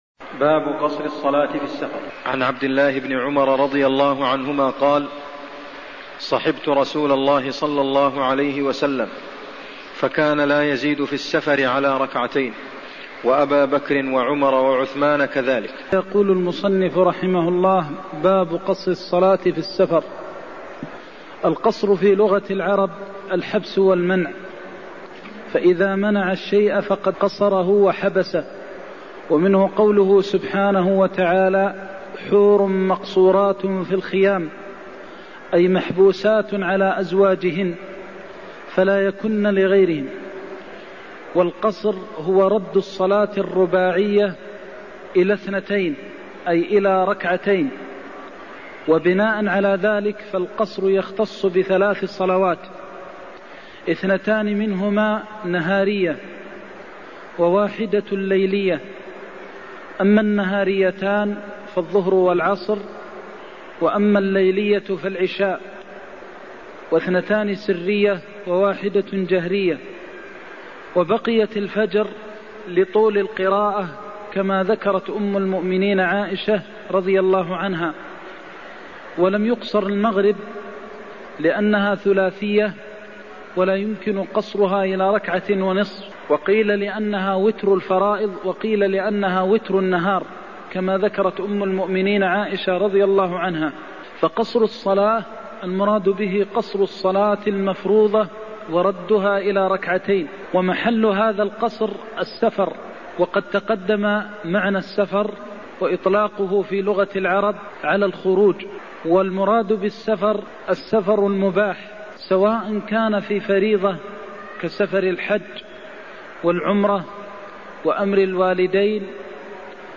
المكان: المسجد النبوي الشيخ: فضيلة الشيخ د. محمد بن محمد المختار فضيلة الشيخ د. محمد بن محمد المختار كان لا يزيد في السفر على ركعتين (127)